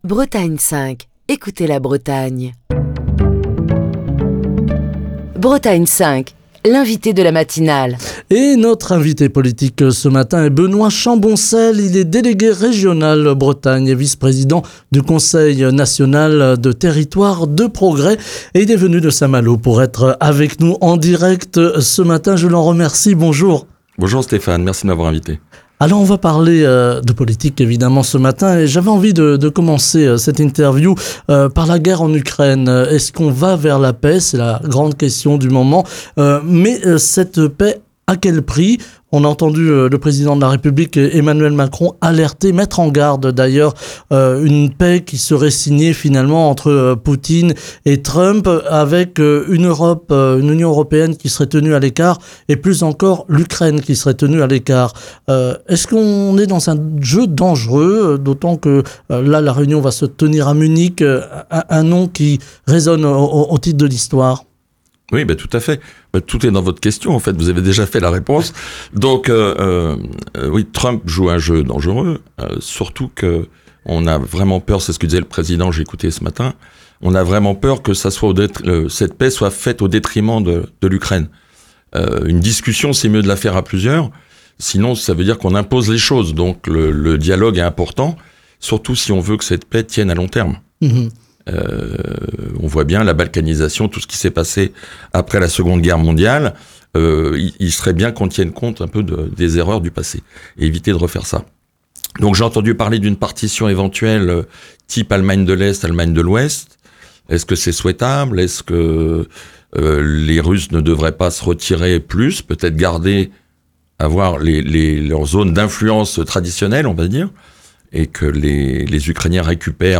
Émission du 14 février 2025.